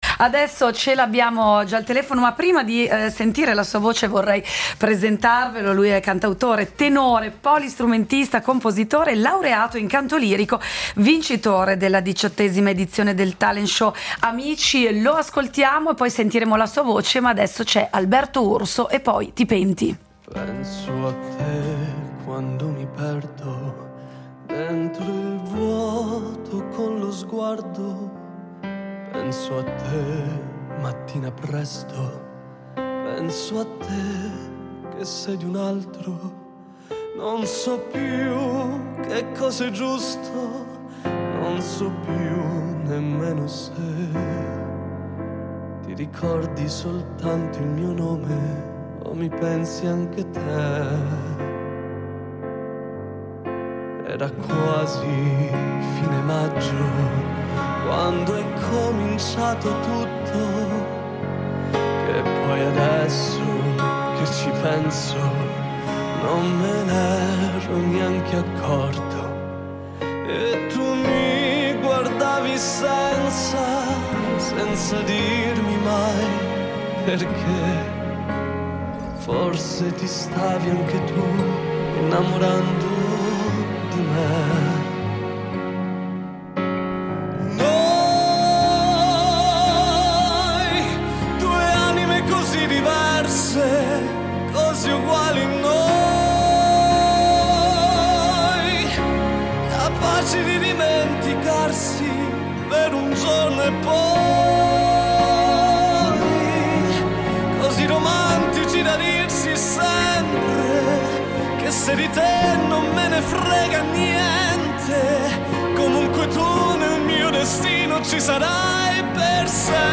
Incontro con Alberto Urso, cantautore, tenore e polistrumentista